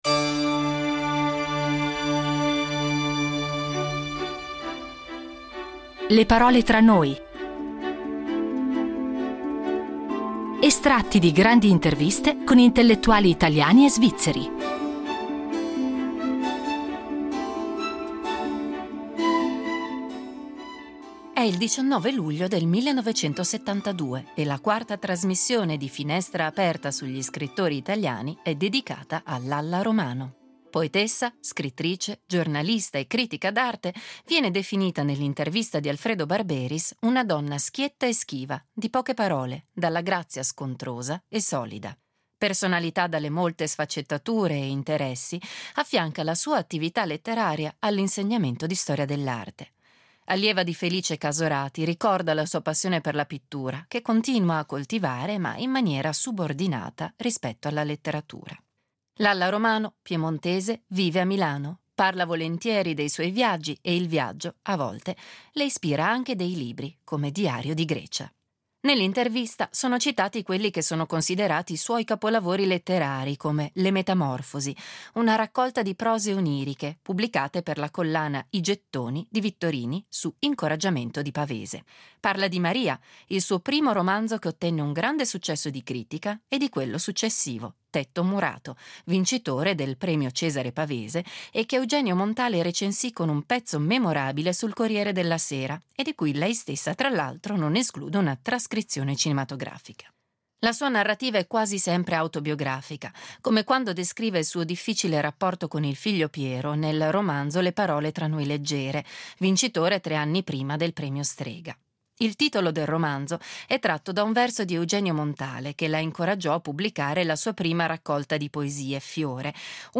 Estratti di grandi interviste con intellettuali italiani e svizzeri